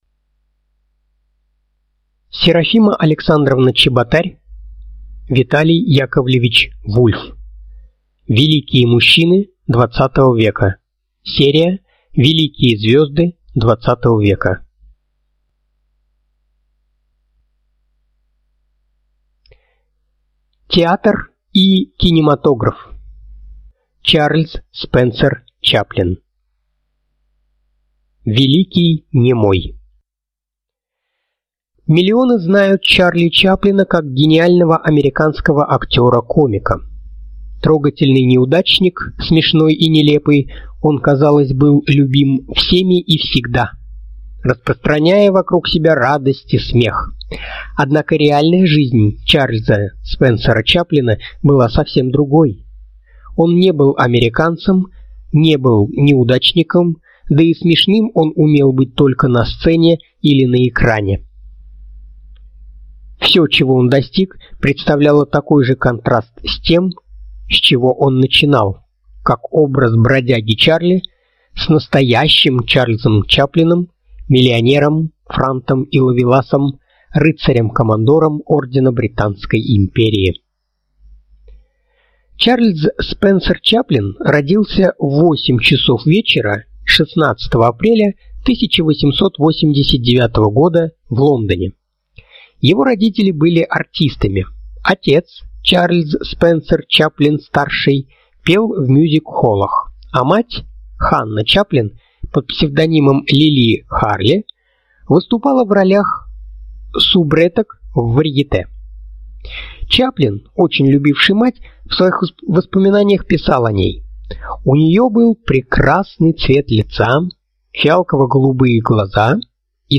Аудиокнига Великие мужчины XX века | Библиотека аудиокниг
Читает аудиокнигу